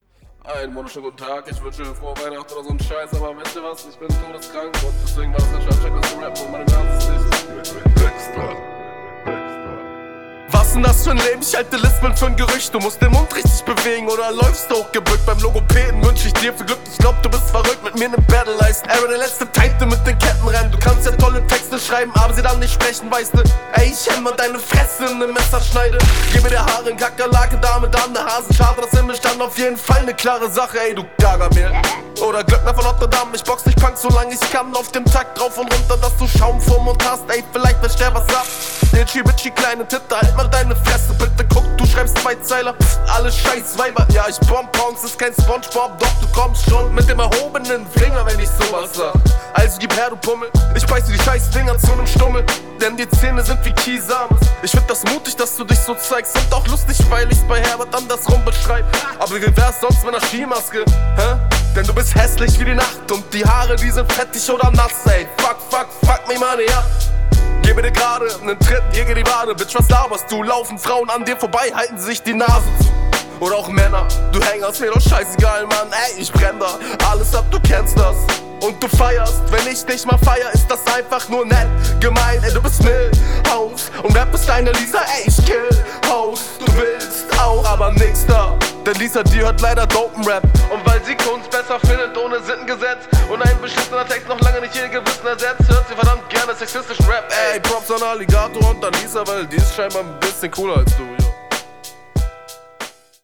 Schöne Battlerunde, gute Punches bei teilweise recht Geile Flow variationen, Geile Milhouse line gefällt mir.